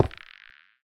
Minecraft Version Minecraft Version snapshot Latest Release | Latest Snapshot snapshot / assets / minecraft / sounds / block / lodestone / place1.ogg Compare With Compare With Latest Release | Latest Snapshot